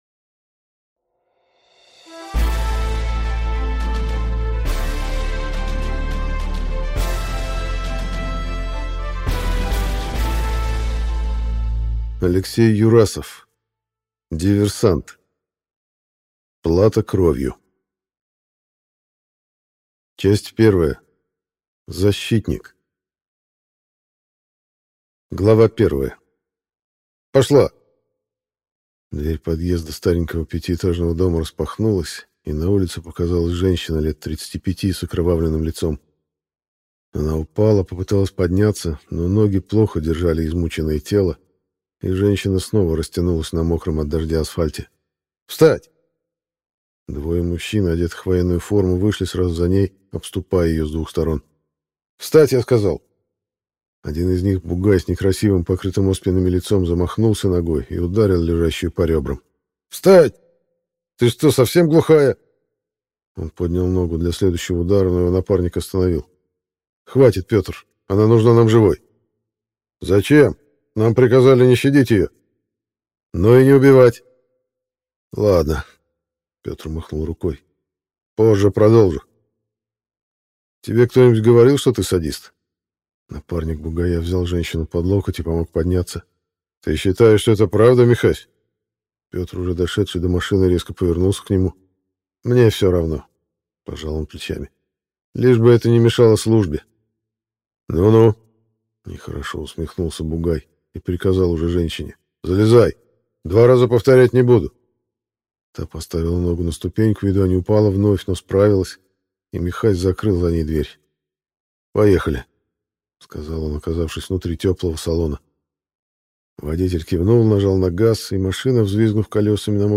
Аудиокнига Диверсант. Плата кровью | Библиотека аудиокниг